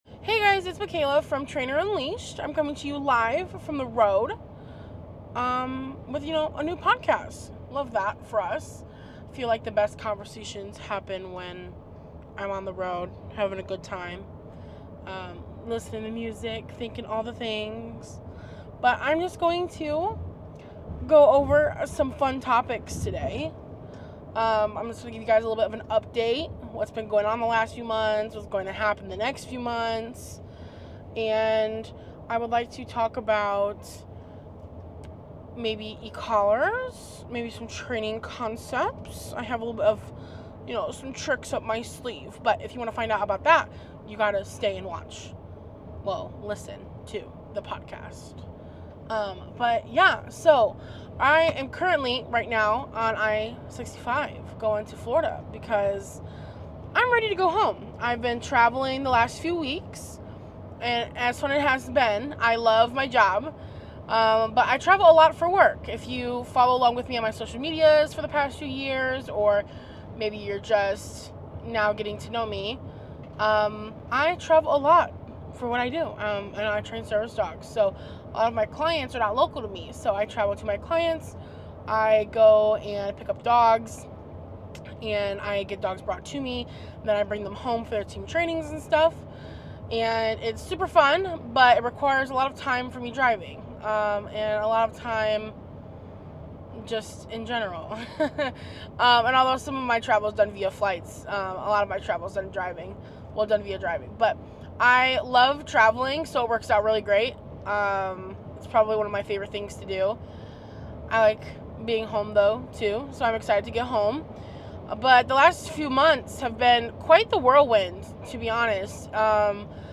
Come chat with me while I am on the road back to the sunshine state.